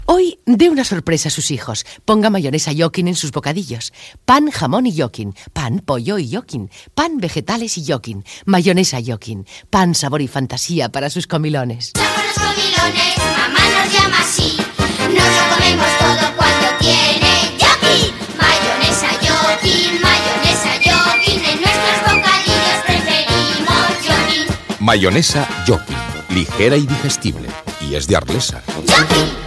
Gènere radiofònic Publicitat Anunciant Mayonesa Yokin